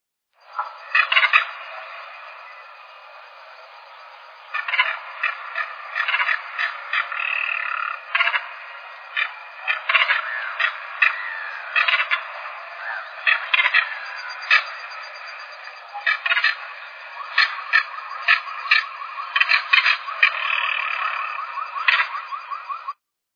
Carancho (Caracara plancus)
Nombre en inglés: Crested Caracara
Fase de la vida: Adulto
Localidad o área protegida: Reserva Ecológica Costanera Sur (RECS)
Condición: Silvestre
Certeza: Vocalización Grabada